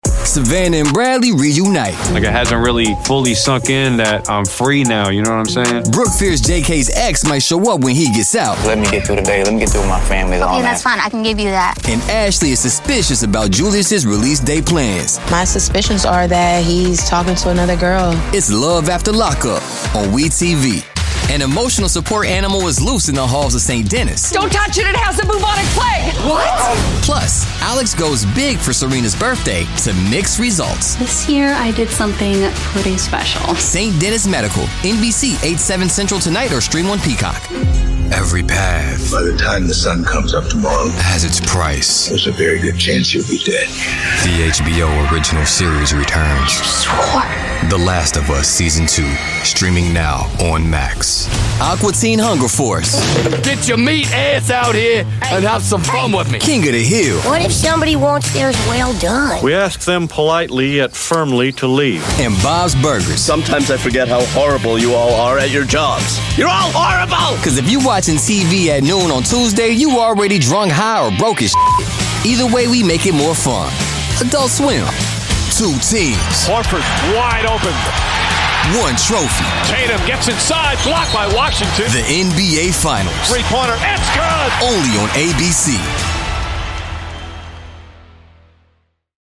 Now, Vibrant, straight talk.
Promo